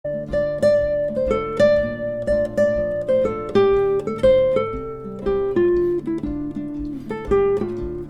- mélodie seule, pour le repiquage, puis pour s'entraîner à caler l'accompagnement sur la mélodie :
couplet, 1ère moitié,